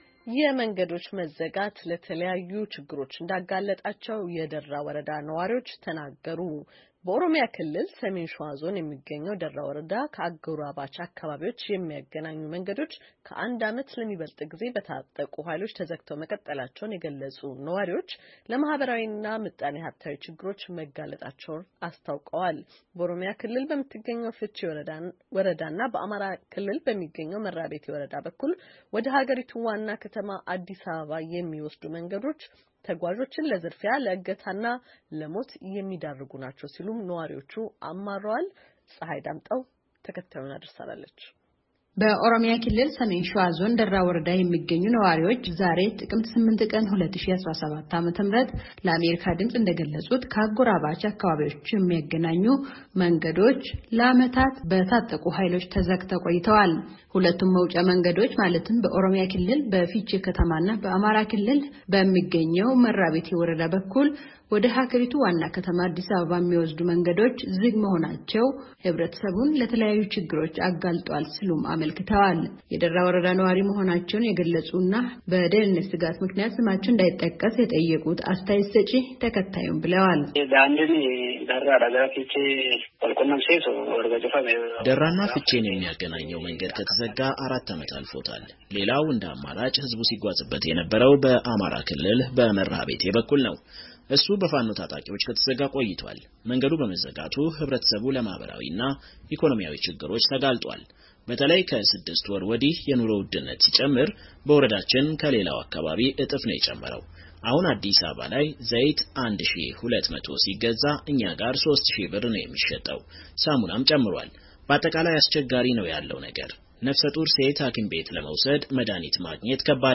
ዋና አስተዳዳሪው ዛሬ ጥቅምት 8 ቀን 2017 ዓ.ም. ከአሜሪካ ድምፅ ጋራ ባደረጉት የስልክ ቃለ ምልልስ፣ የኦሮሞ ነጻነት ሠራዊት ታጣቂዎች በዞኑ አምሰት ወረዳዎች ፣ የፋኖ ታጣቂዎች ደግሞ በሦስት ወረዳዎች ውሰጥ እንደሚንቀሳቀሱ ጠቅሰው ለመንገዶቹ መዘጋትና በአካባቢው ላለው የጸጥታ ችግርም ሁለቱን ቡድኖች ተጠያቂ አድርገዋል።